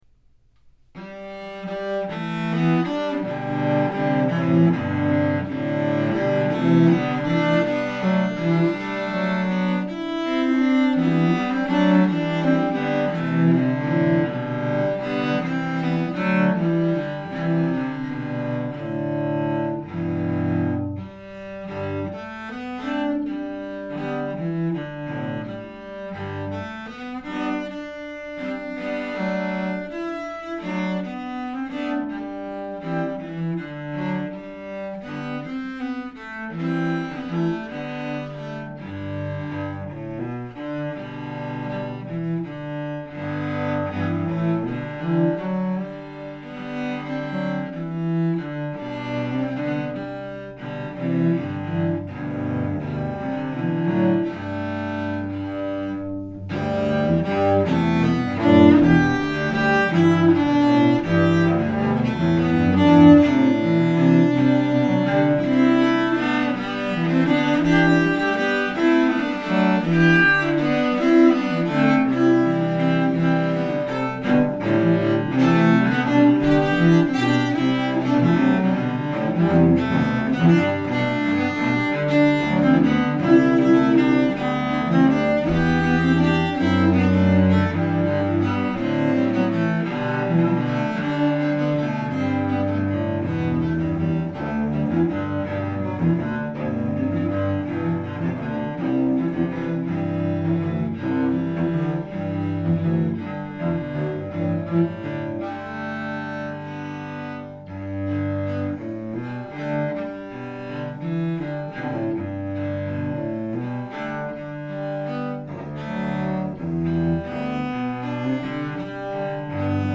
The melody is distinctly American in sentiment, evoking expansive landscapes across flowing pentatonic notes.
This arrangement for cello trio demonstrates that the cello is uniquely suited to play this tune, with several lines that could be excerpted as solos. In trio, the melody is repeated with only subtle variations as the accompaniment parts provide rhythmic variety with rich textures. Contemporary harmonies stem from the linear part writing to seamlessly blend with the simple melody. The result is a clearly twenty-first century rendition with surprising complexity stemming from just three parts.
Land-of-Rest-Cello-Trio.mp3